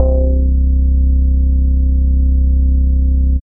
Organ (7).wav